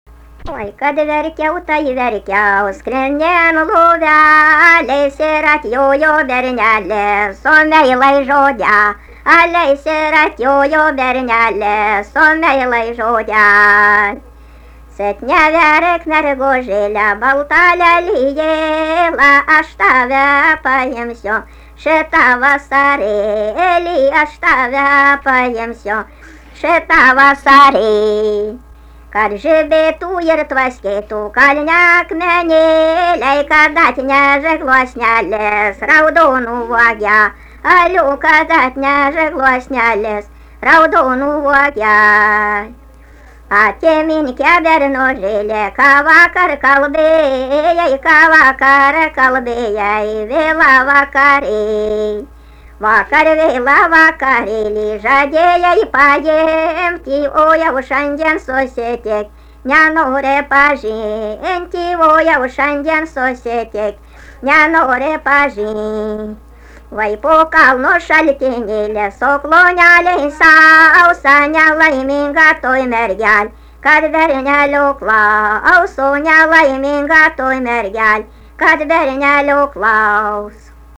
Erdvinė aprėptis Pauosupė
Atlikimo pubūdis vokalinis
Fone kartais pasigirsta 2 balsas